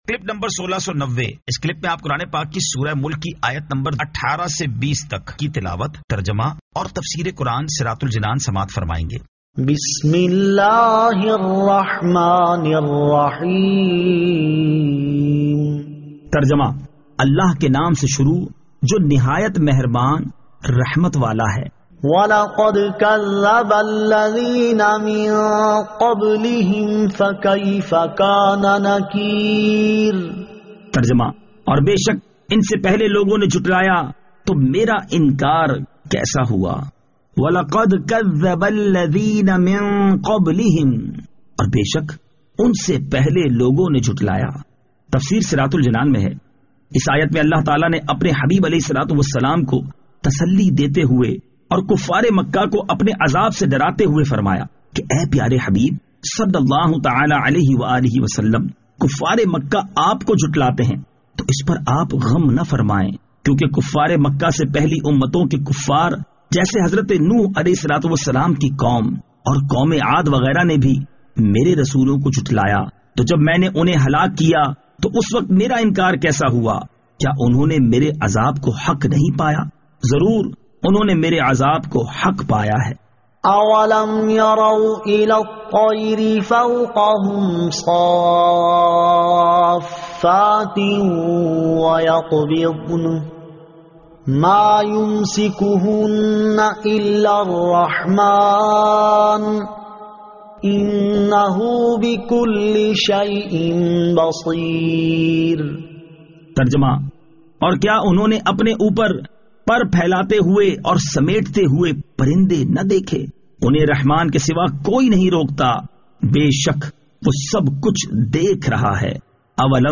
Surah Al-Mulk 18 To 20 Tilawat , Tarjama , Tafseer